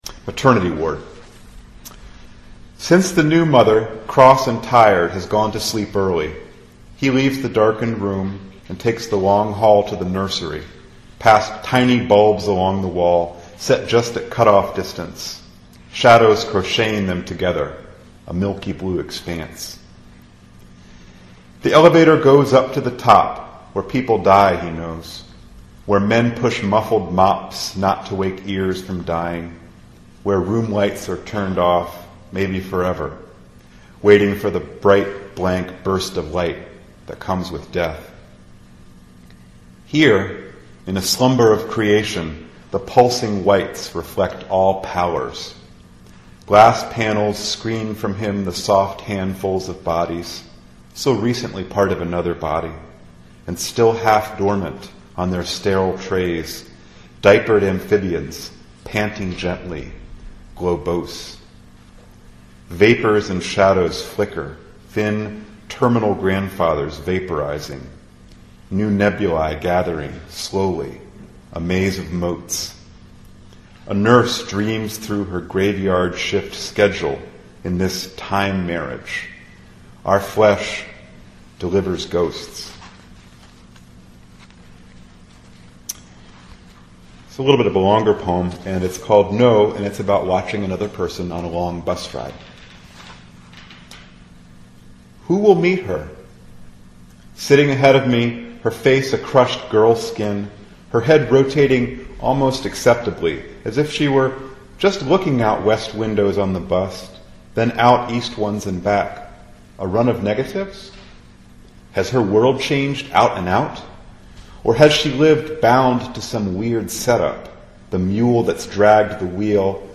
Cal State Bakersfield, April 15, 2010